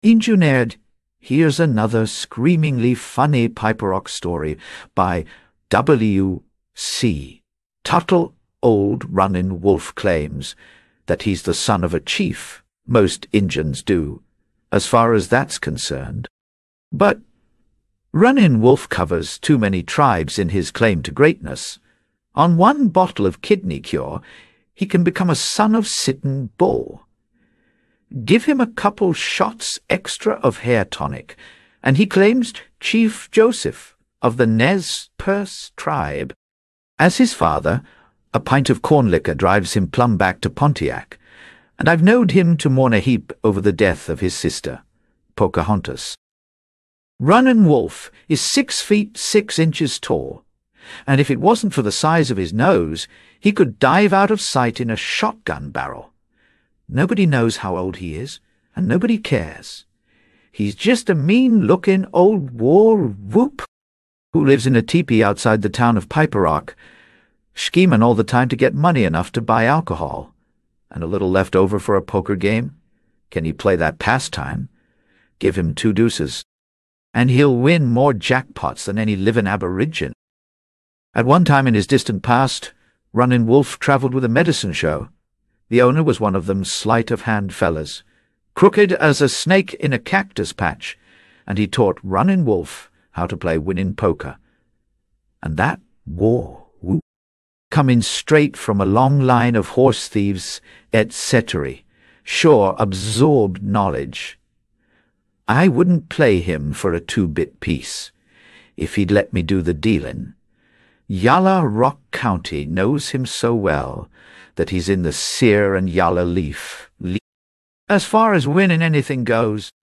Injuneered_by_W._C._Tuttle_en_sample.mp3